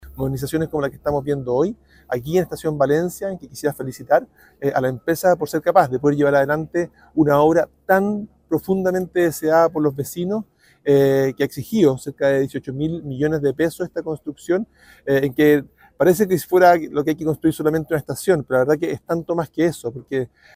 Asimismo, el secretario de Estado detalló que la obra beneficiará a cerca de 600 mil pasajeros al año y que habrá una instalación moderna de alto estándar.